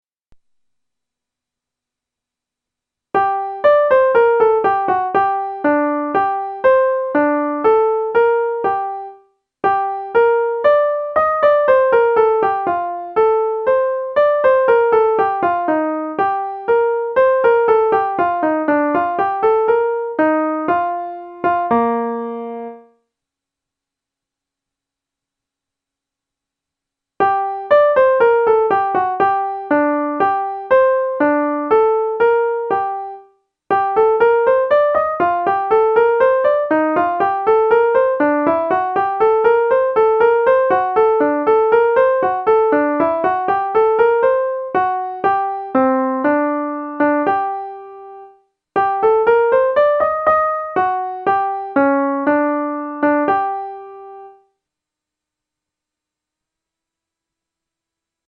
SATB)Télécharger